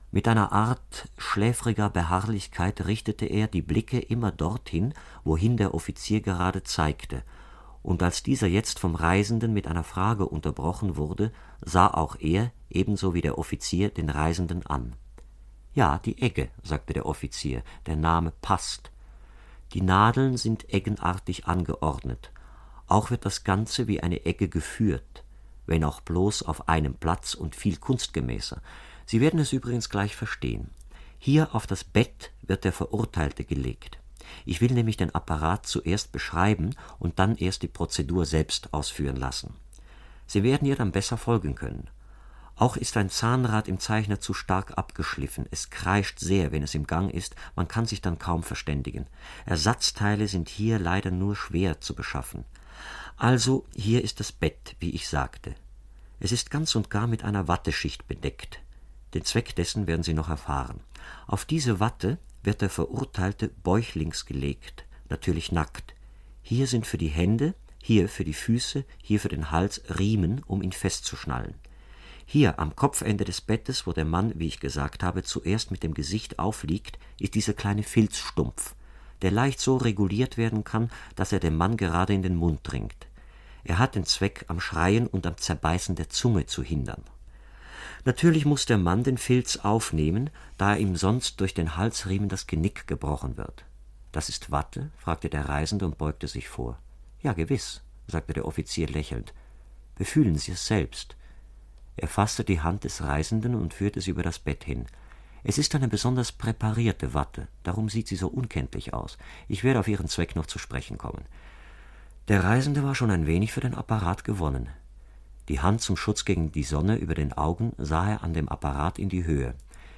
In der Strafkolonie - Franz kafka - Hörbuch